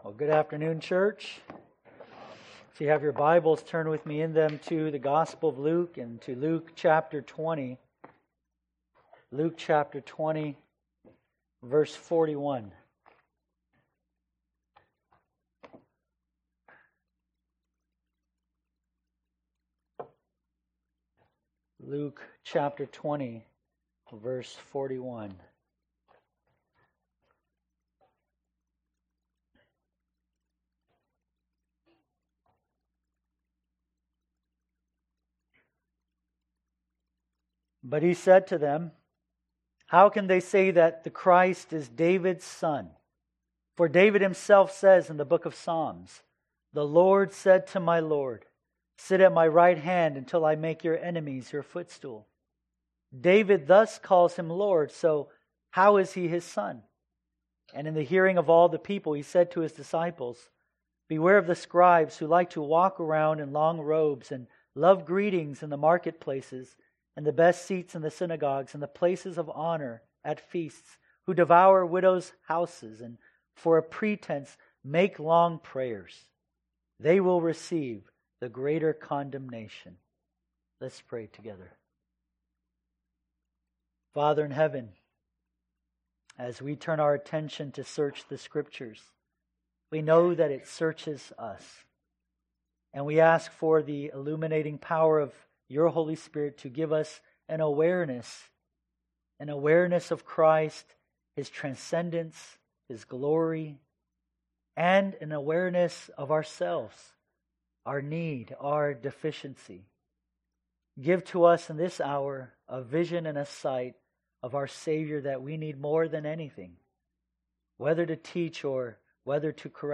Sunday Sermons – Pillar Baptist Church